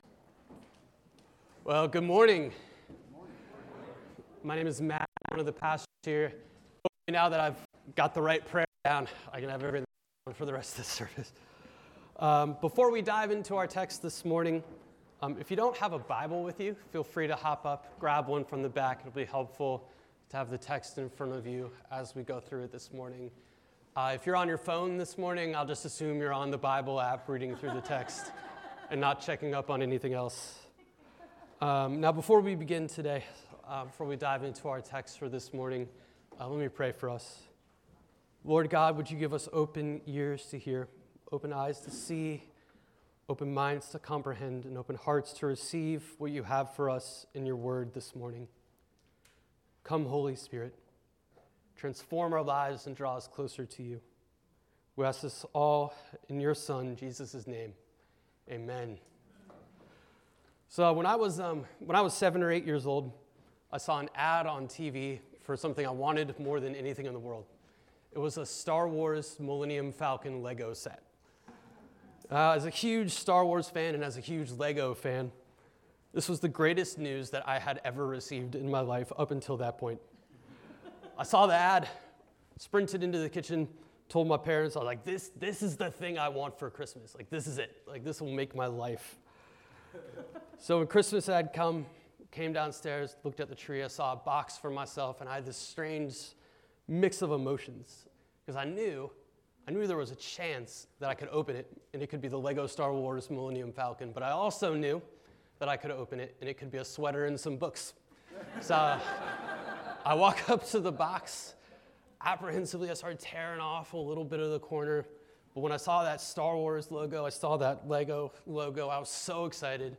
On the Second Sunday of Christmas